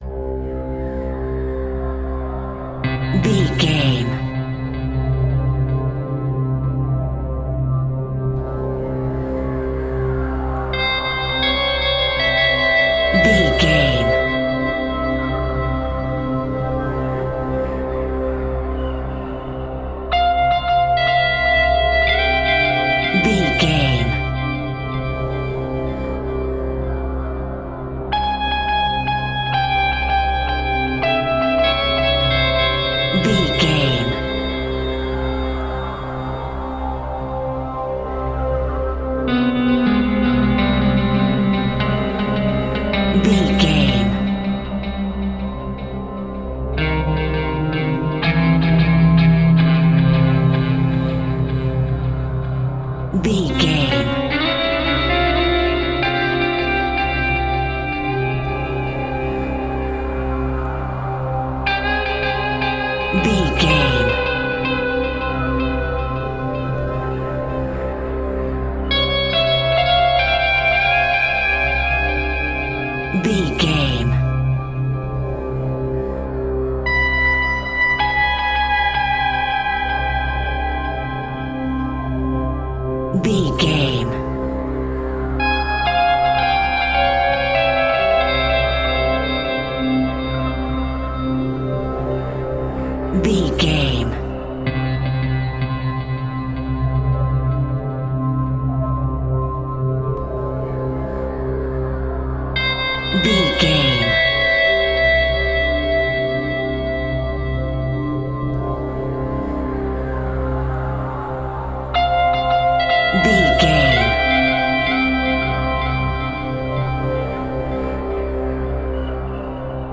Phrygian
Slow
ambient
indian synths
indian sitar